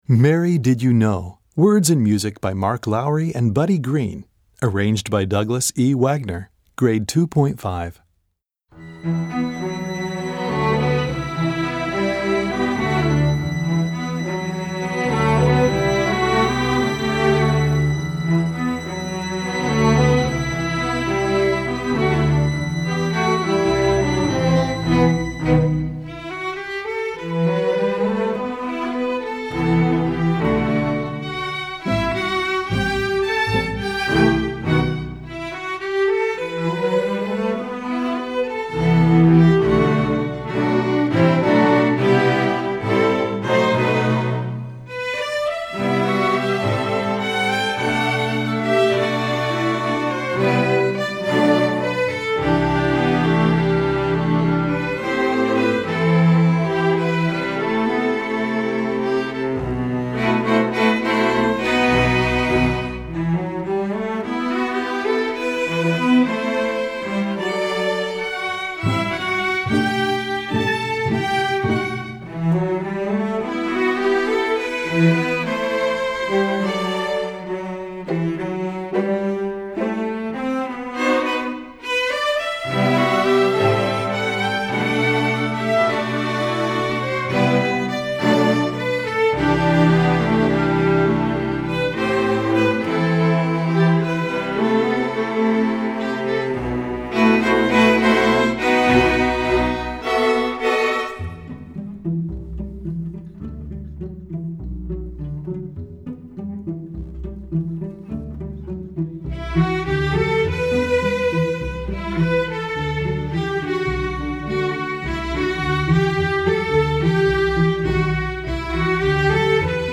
Gattung: Streichorchester
Besetzung: Streichorchester
contemporary Christmas standard
sensitively scored string orchestra setting